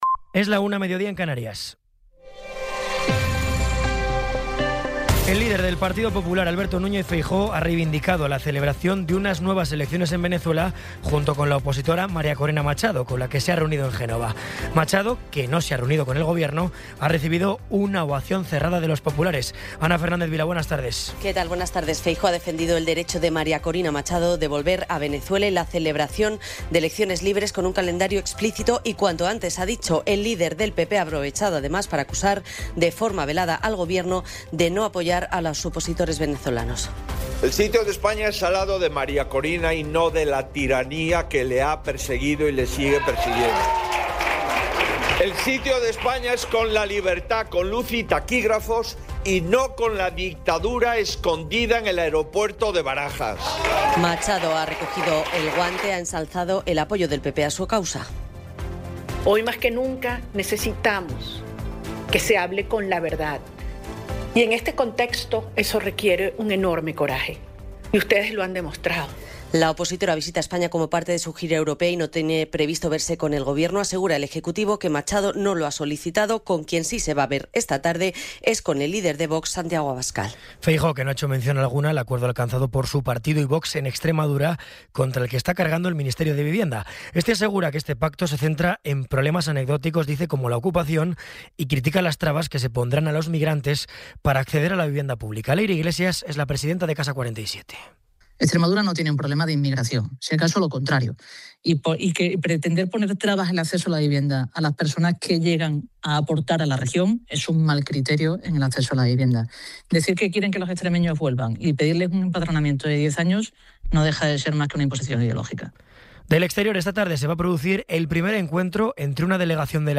Resumen informativo con las noticias más destacadas del 17 de abril de 2026 a la una de la tarde.